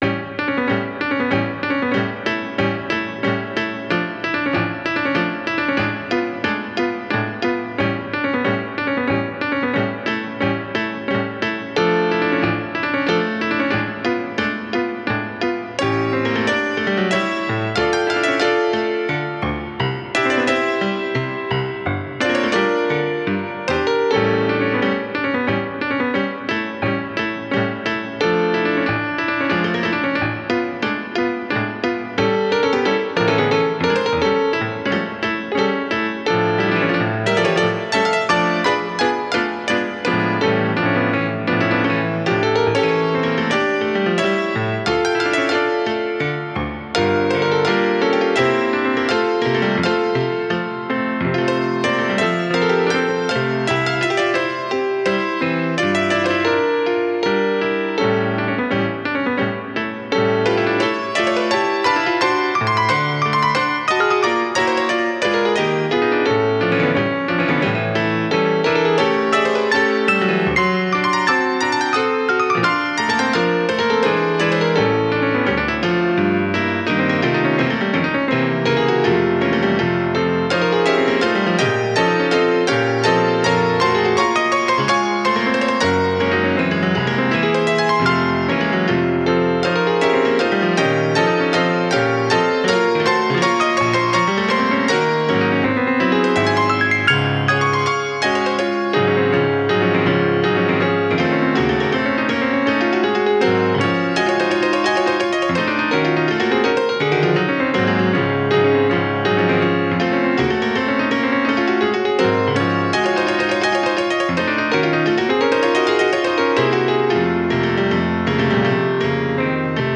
Enrique Granados, Fandango del Candil de la Suite para piano Goyescas
fandango